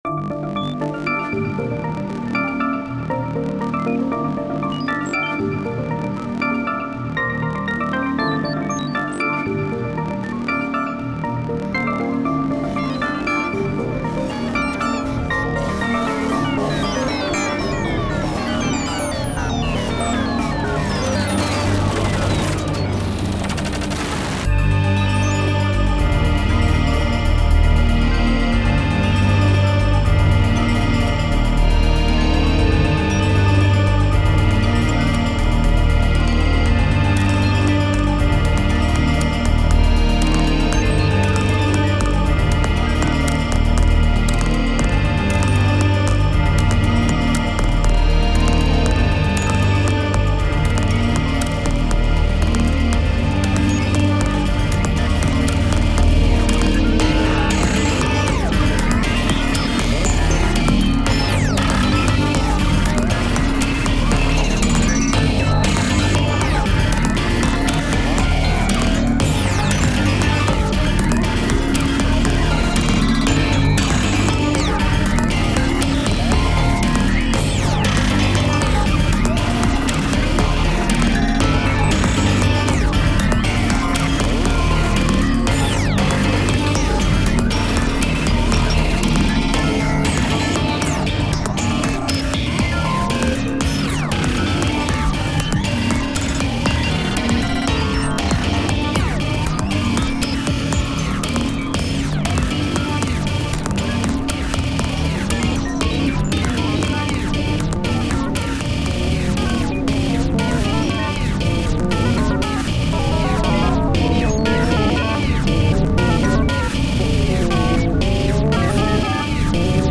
タグ: ゲーム 変わり種 怪しい 電子音楽 コメント: ゲームで隠しモードが解放されるみたいなシーンをイメージした楽曲。